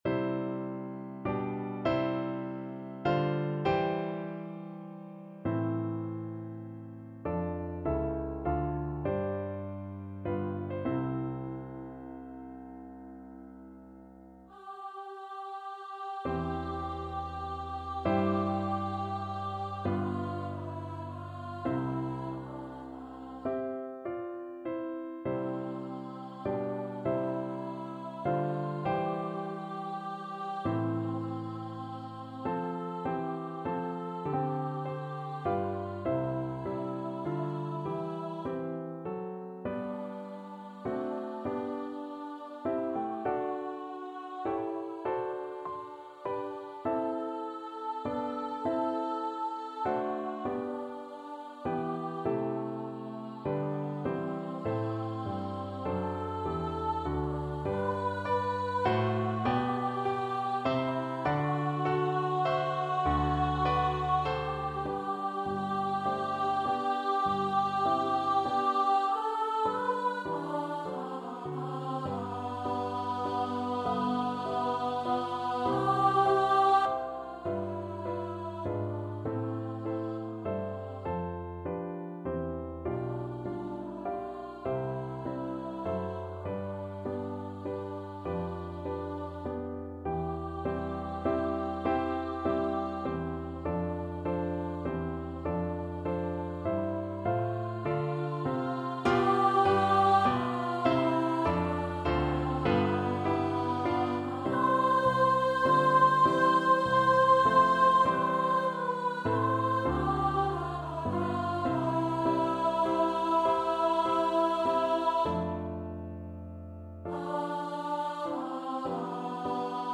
Free Sheet music for Contralto Voice
Contralto
C major (Sounding Pitch) (View more C major Music for Contralto Voice )
Largo